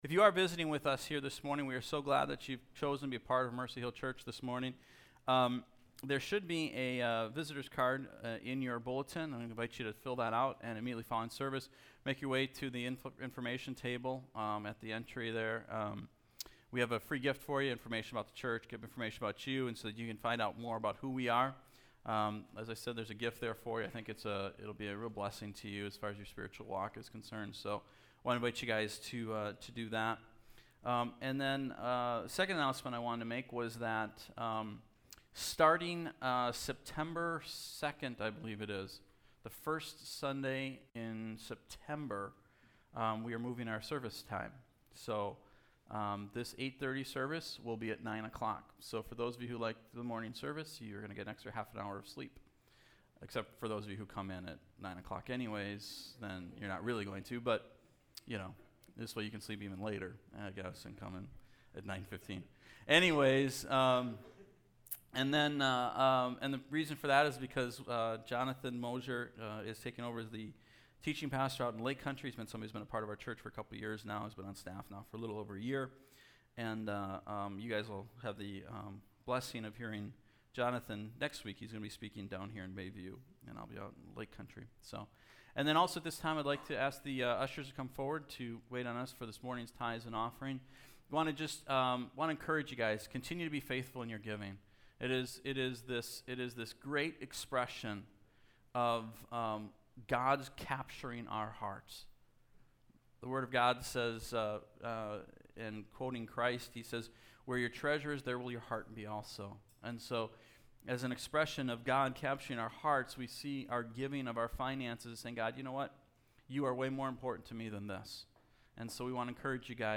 Bay View Sermons — Mercy Hill Church